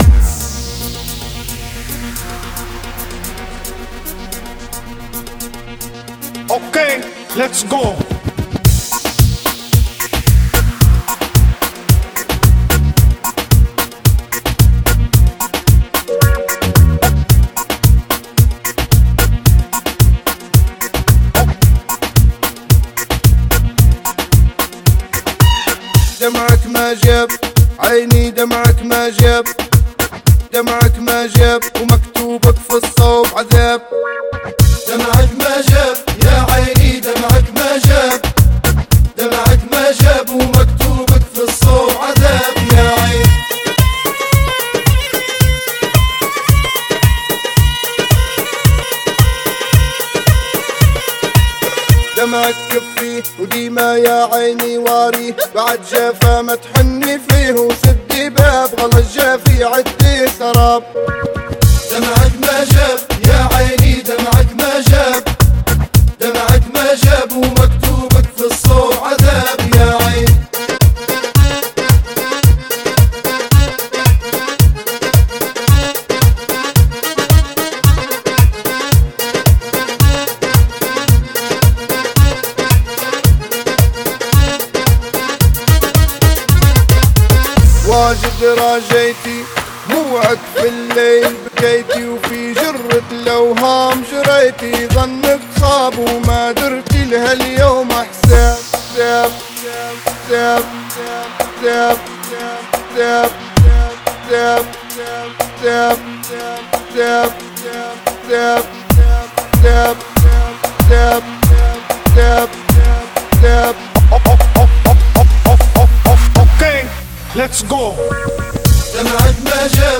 это энергичная композиция в жанре электронной музыки